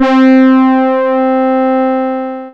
MUTE HORN.wav